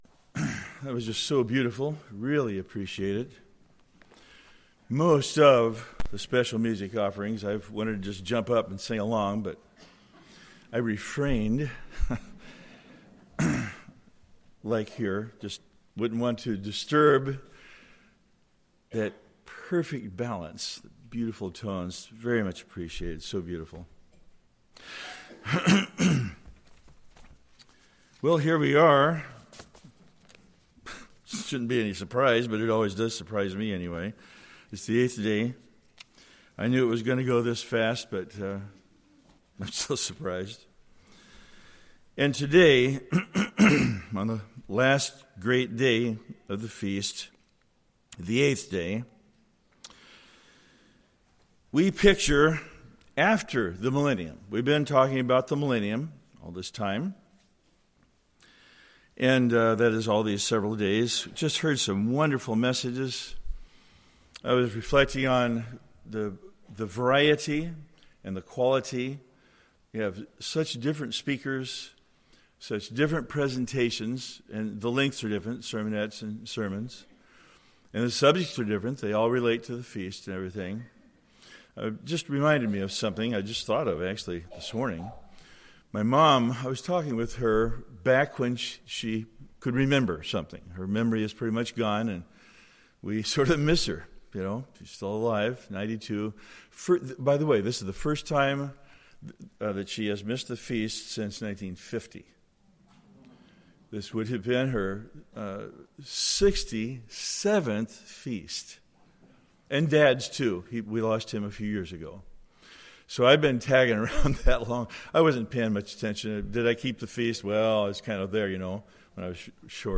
This sermon was given at the Oconomowoc, Wisconsin 2016 Feast site.